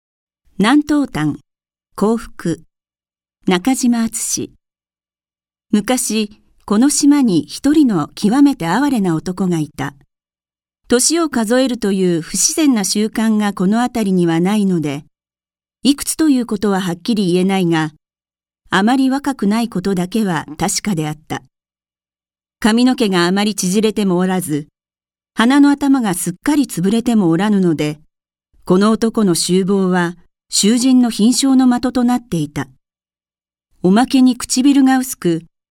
朗読ＣＤ　朗読街道145「南島譚 幸福・夫婦・鶏」中島敦
朗読街道は作品の価値を損なうことなくノーカットで朗読しています。